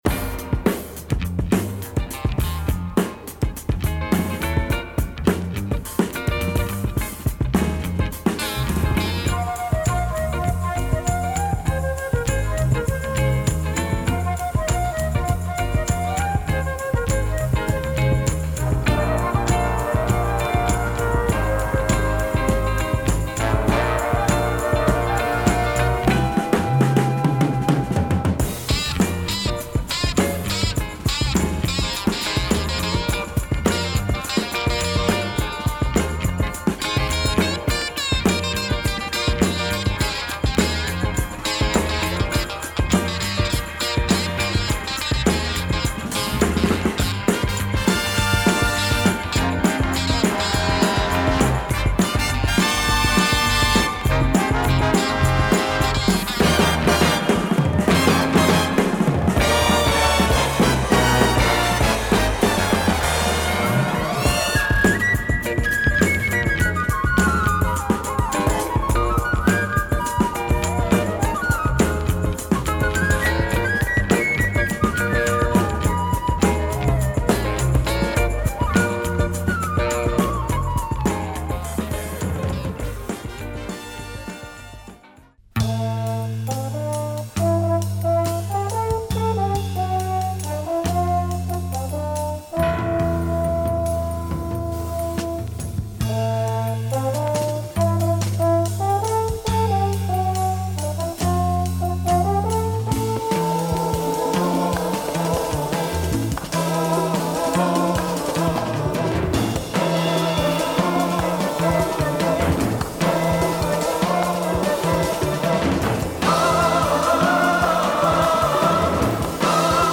library music
Superb mix of psychedelic pop, prog and groove